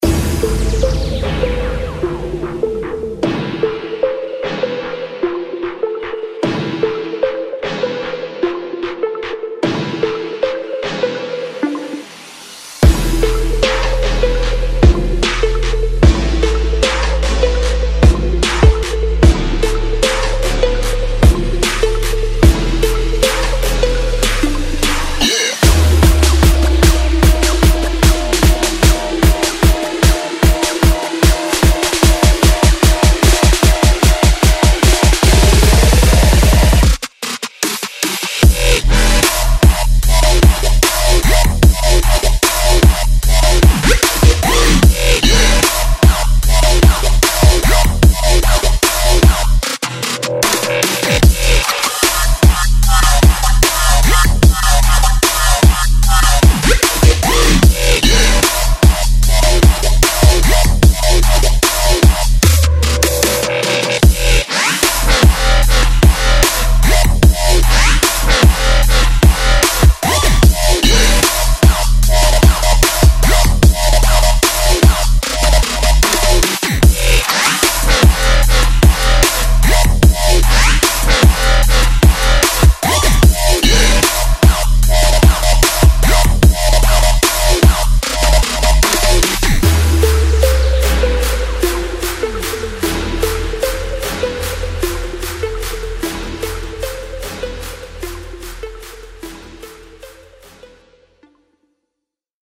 现在，您可以使用227个他的招牌样本，包括清晰的鼓点，摇摆的低音，清爽的FX和人声。
47 LOOPS
180 ONE SHOTS